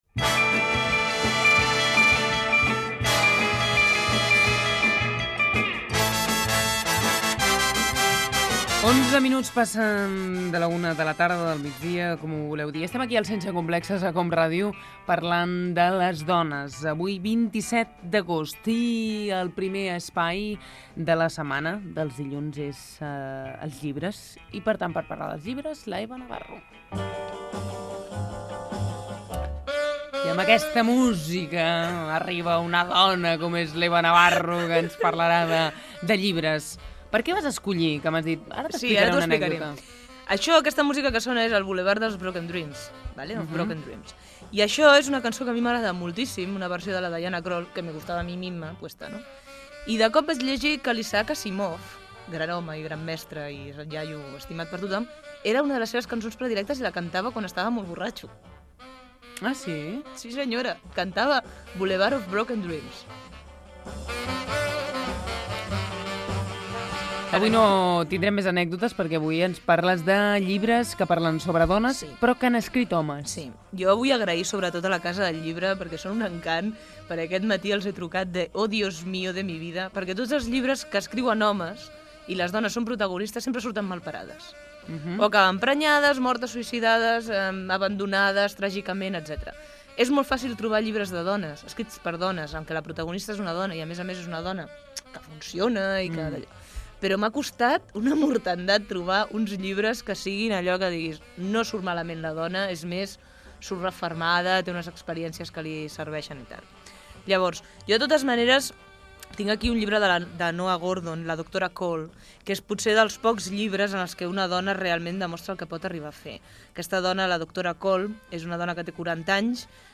Hora, data, secció de llibres amb la participació dels oients.
Entreteniment
Fragment extret de l'arxiu sonor de COM Ràdio